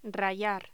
Locución: Rallar
voz
Sonidos: Voz humana